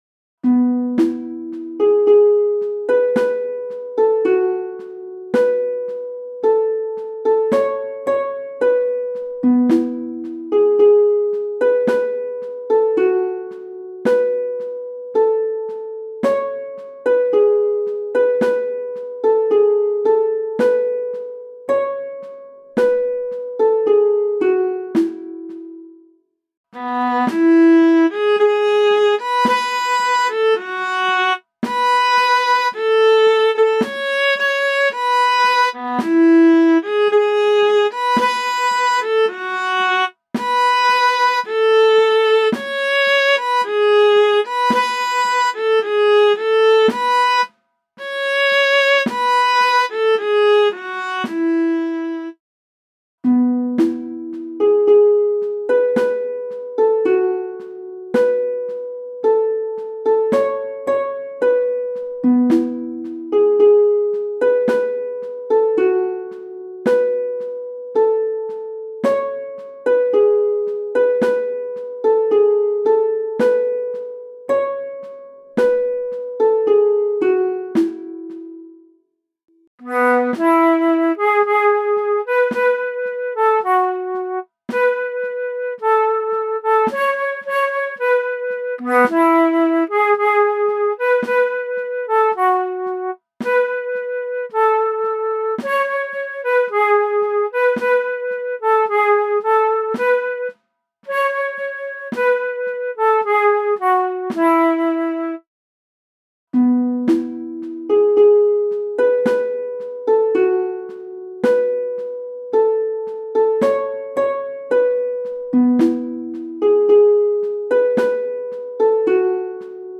MIDI von 2015 [5.310 KB] - mp3
midi_der-gruene-wagen_1-stimmig_320.mp3